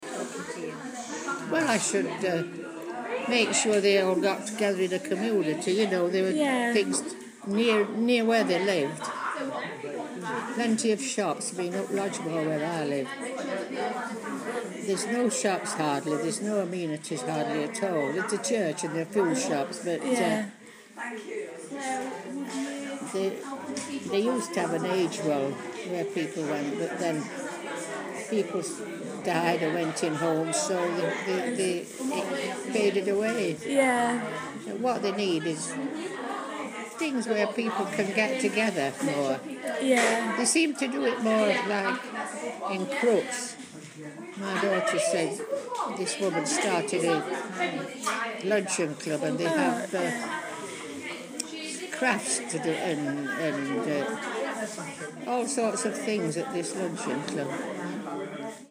asks residents at Prior Bank Care Home what they would do to reduce loneliness